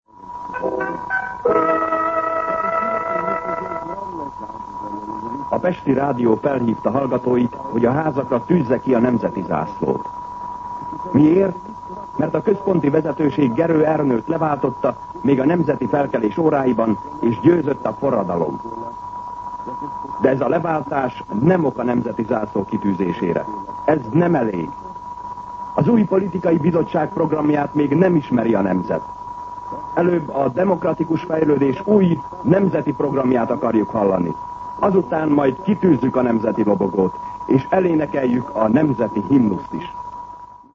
Szignál
MűsorkategóriaKommentár